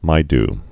(mīd)